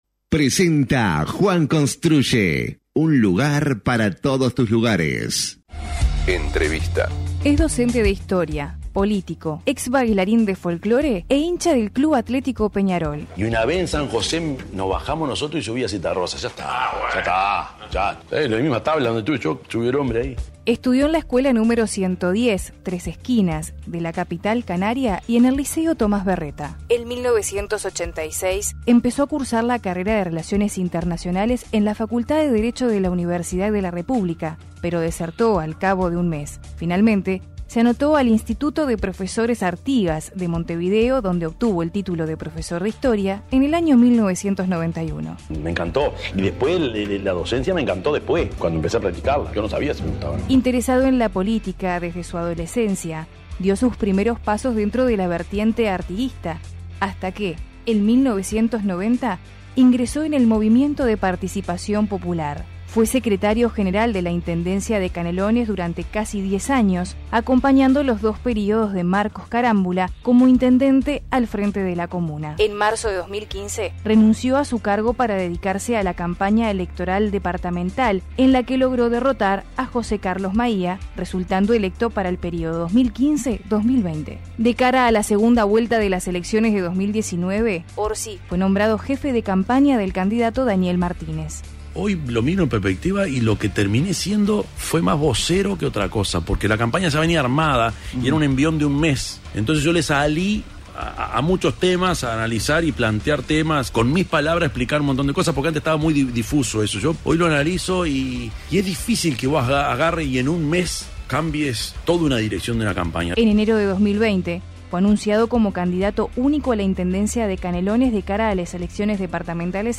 Entrevista a Yamandú Orsi